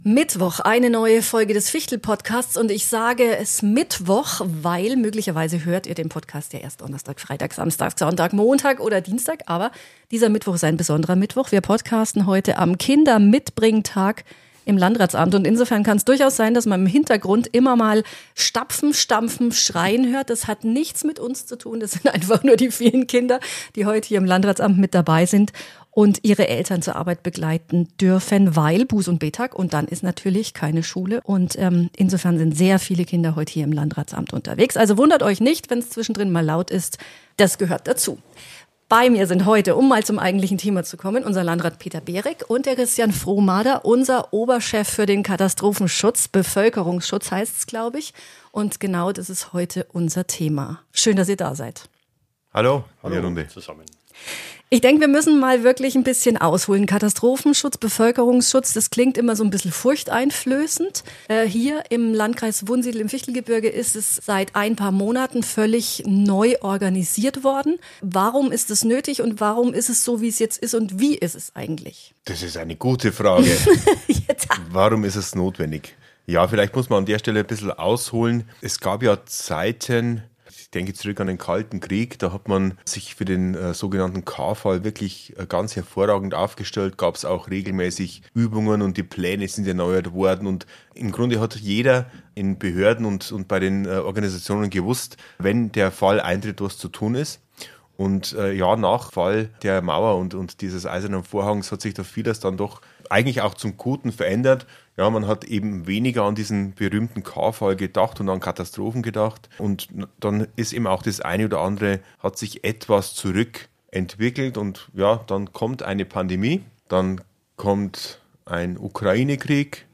Ein aufschlussreiches Gespräch über Verantwortung, Zusammenarbeit und die Sicherheit der Bevölkerung.